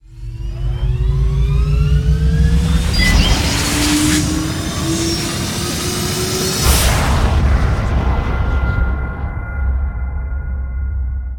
shipassend.ogg